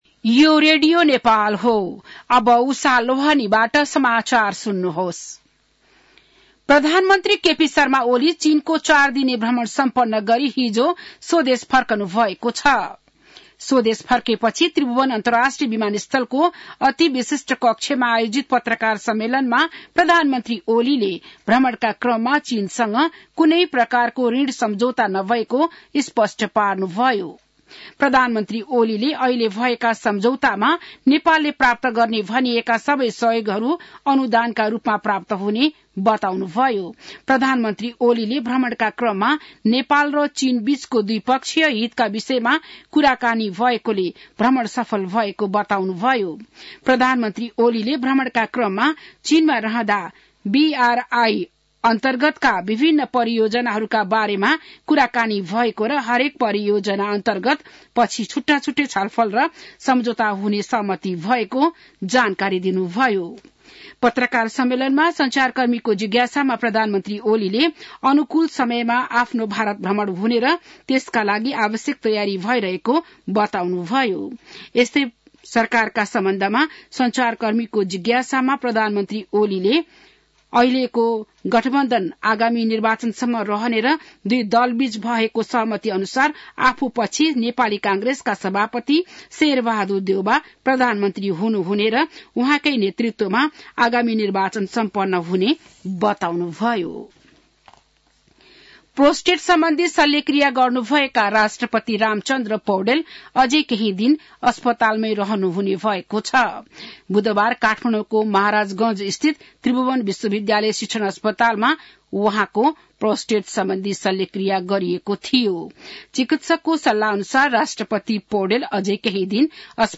बिहान १० बजेको नेपाली समाचार : २२ मंसिर , २०८१